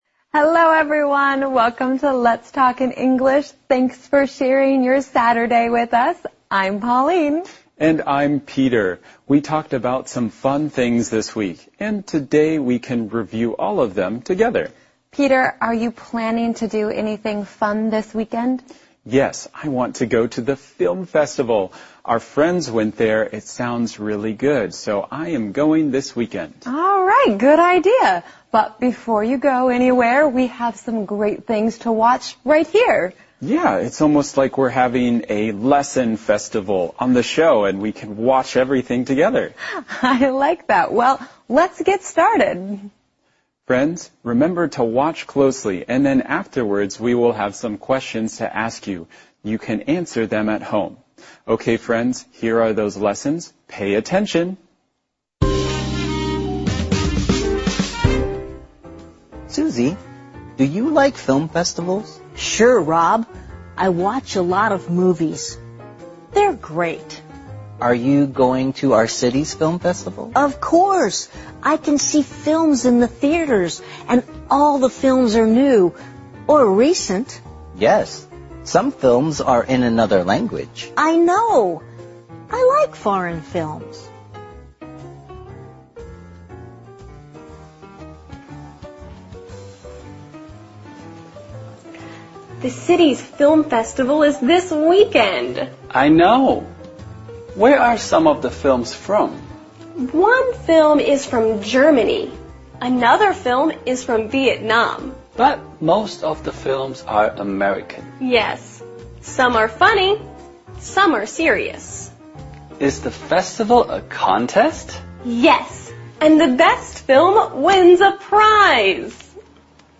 《空中英语教室》主要以收录世界最新时尚资讯、热点话题、人物、故事、文化、社会现象等为主，以谈话聊天类型为主的英语教学节目。